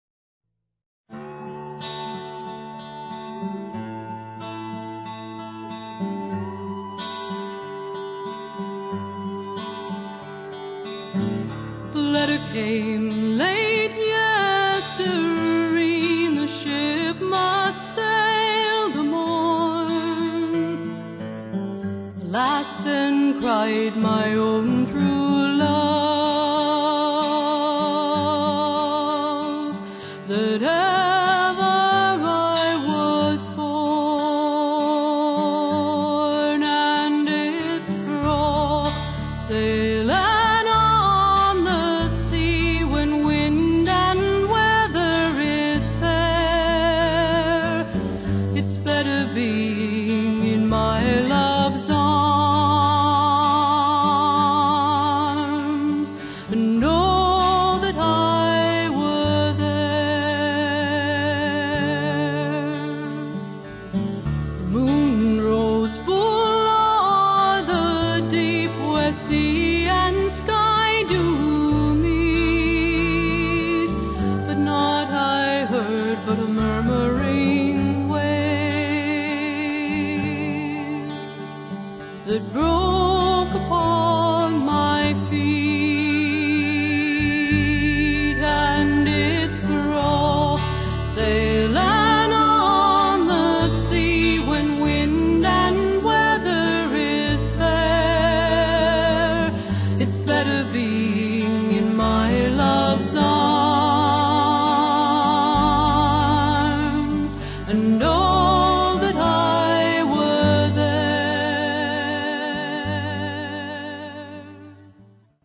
Her voice is as clear as a lark but has the strength of an eagle.